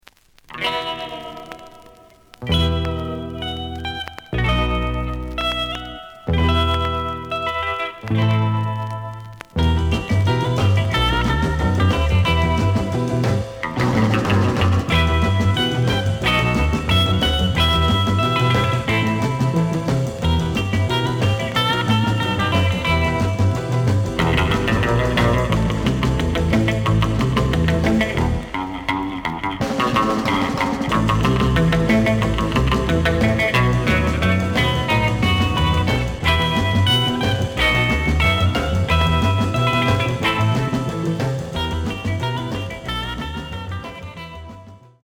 試聴は実際のレコードから録音しています。
●Genre: Rock / Pop
●Record Grading: VG- (両面のラベルにダメージ。傷は多いが、プレイはまずまず。)